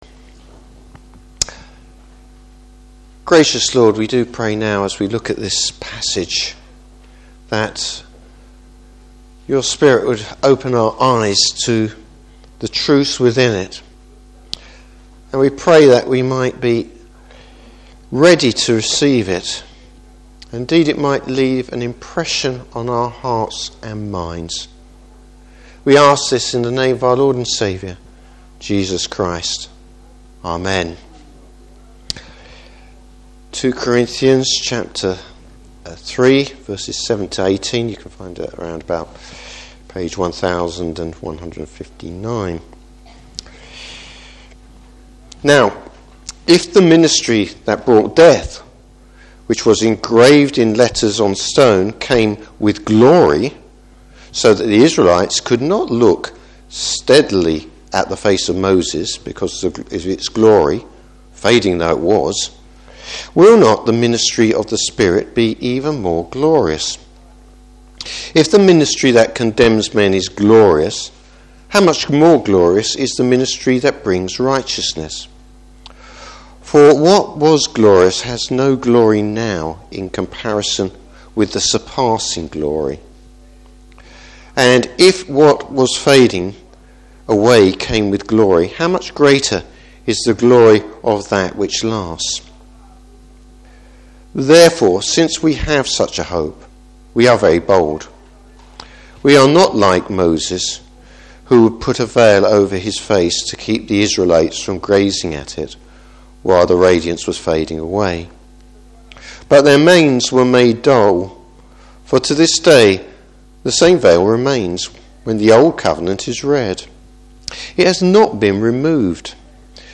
Service Type: Morning Service Old vs New Covenant.